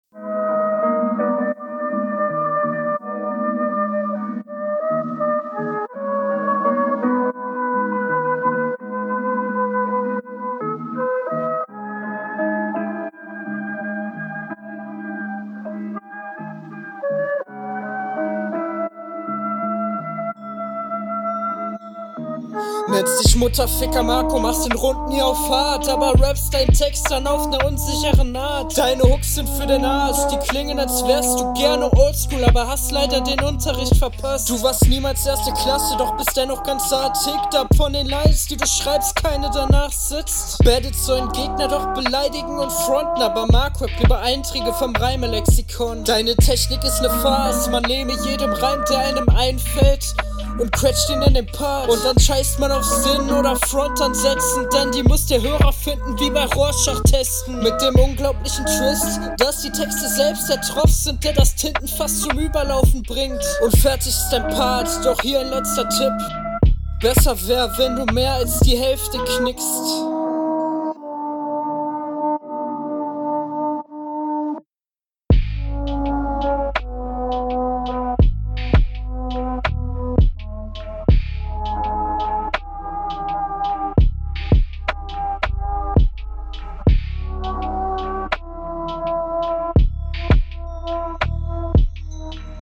Also erstmal finde ich die Mische irgendwie sehr anstrengend beim zuhören.